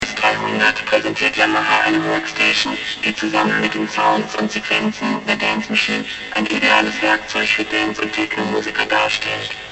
The result was a 16-band 24db/octave vocoder, with a spectrum analyzer, band patch matrix, variable envelope followers,  panorama and a 3D look and feel skin.
here for the robot example. The speech used for this demo sounds are in german, from a female speaker talking about the features of a Yamaha workstation of those years.
Voxx-Robot.mp3